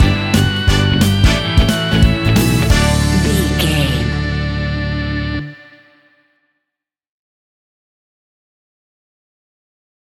Fast paced
Uplifting
Ionian/Major
D
pop
pop rock
fun
energetic
acoustic guitars
drums
bass guitar
electric guitar
piano
organ